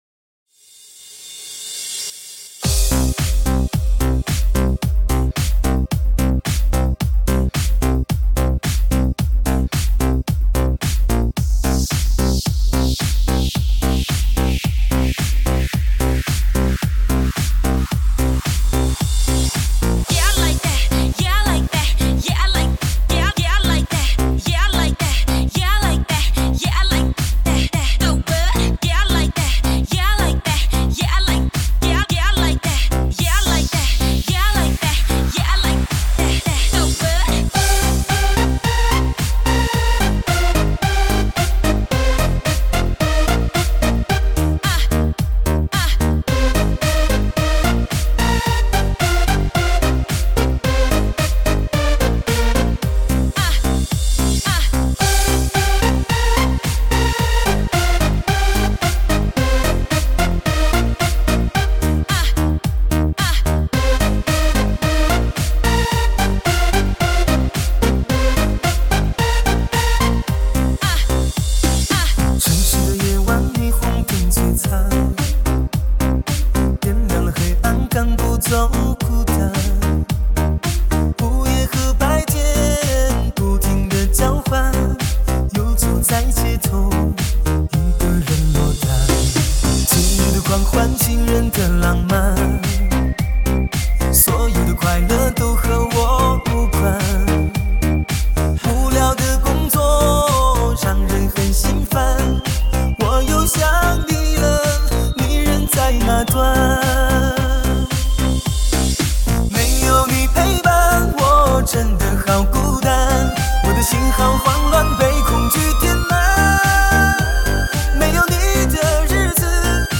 高品质车载慢摇圣曲